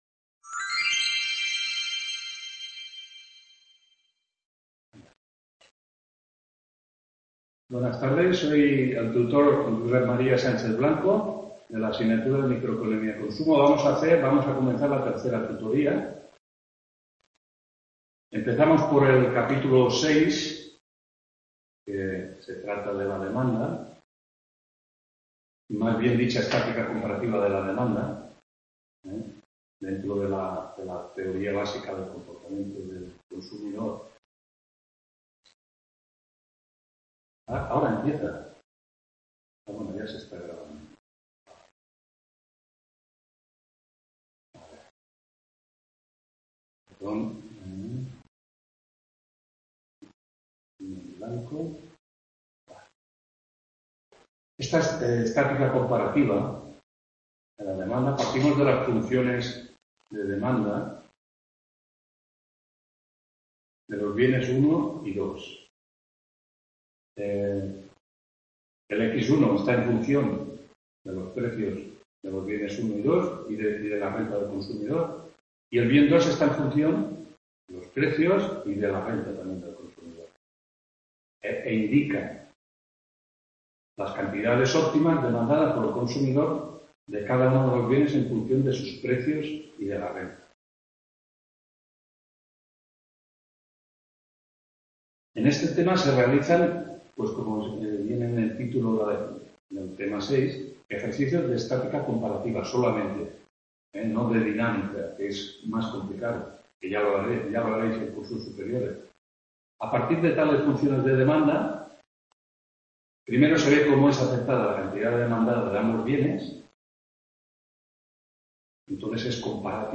3ª TUTORÍA AVIP 02-12-14 MICROECONOMÍA: CONSUMO tutor…